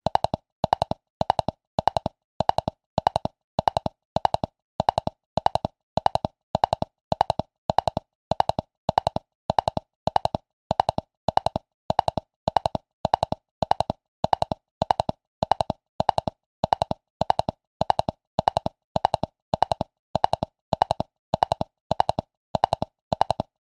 Звук мультяшного топота лошади